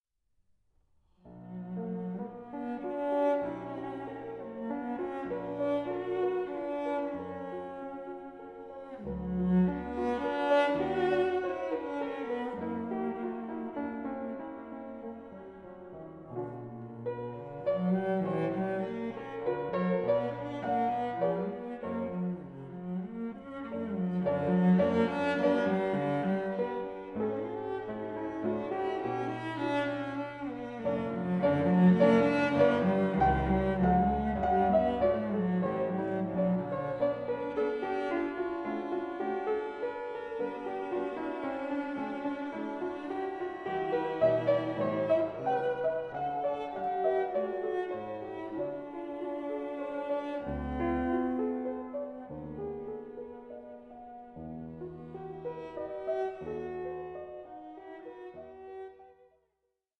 1837 Érard fortepiano